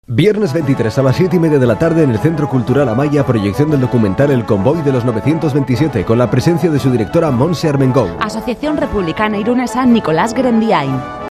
Maiatzan "Irun Irratian" emango dituzten iragarki laburrak